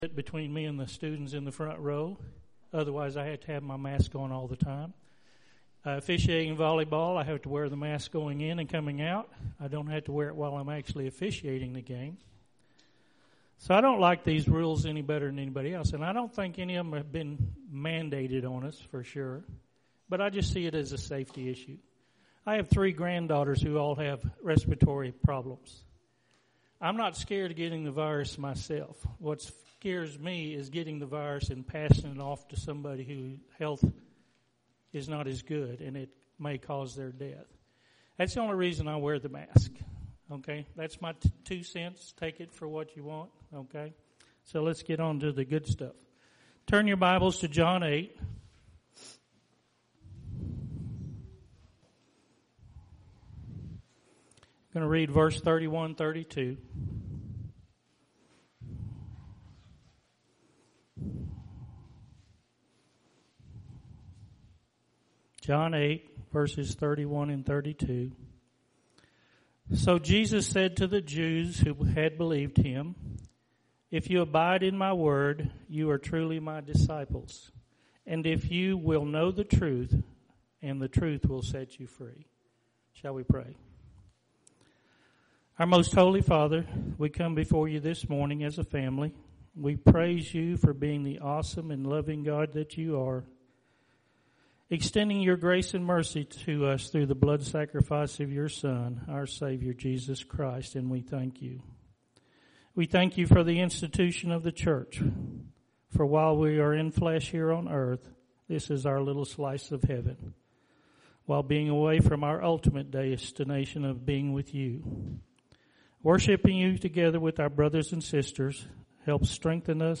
September 13th – Sermons